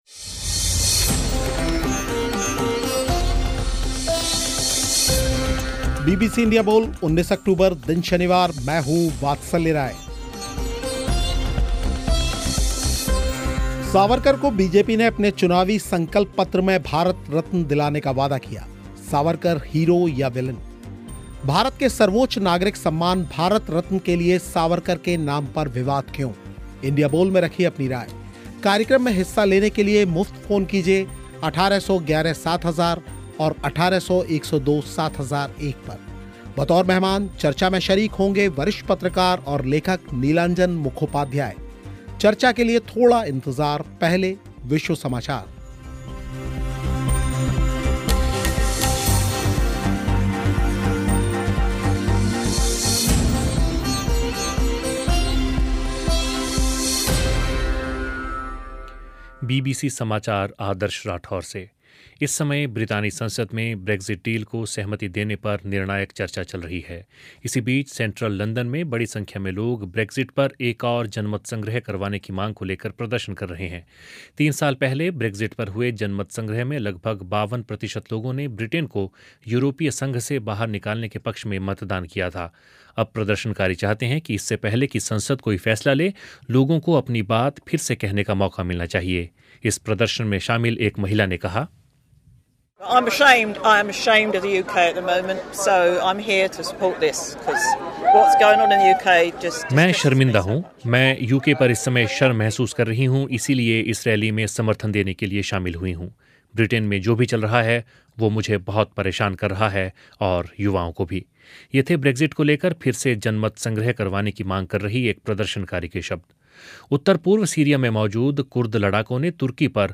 इसी विषय पर इंडिया बोल में हुई चर्चा
श्रोताओं ने भी रखी अपनी बात